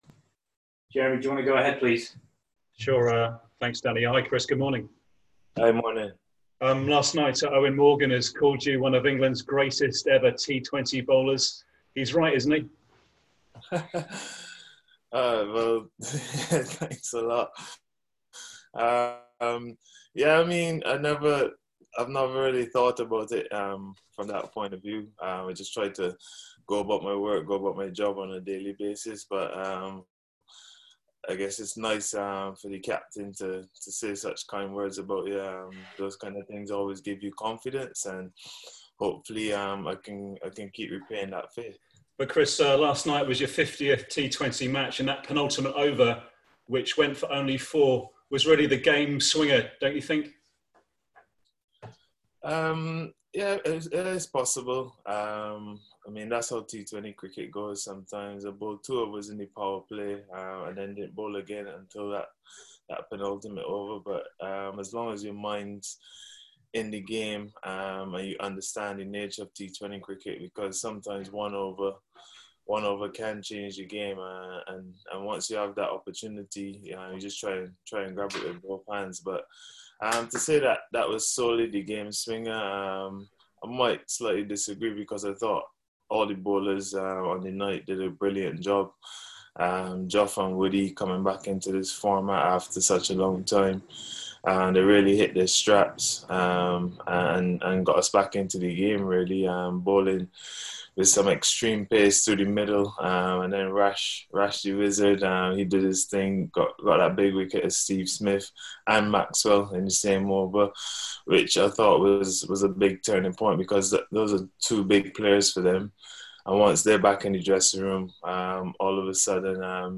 England’s virtual media conference with all-rounder Chris Jordan